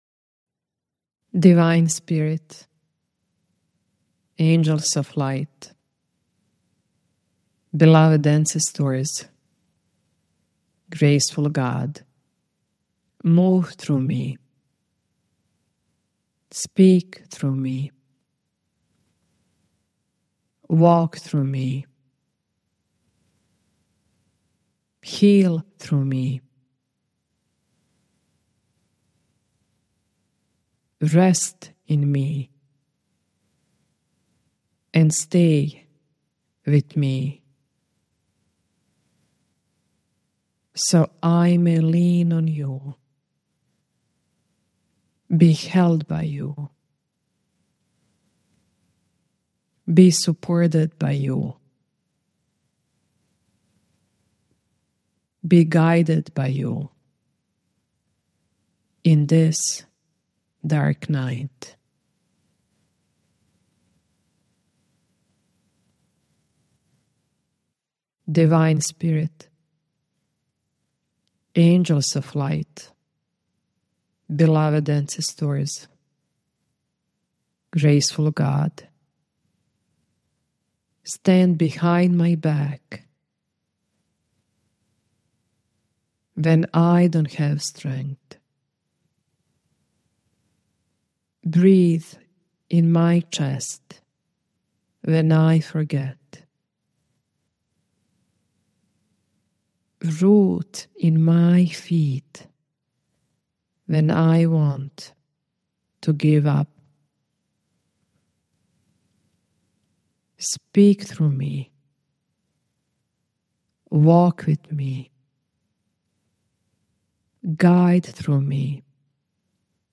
The repetition — move through me, walk through me, rest in me — mirrors the natural rhythm of the body’s regulation cycle: expansion, contraction, rest.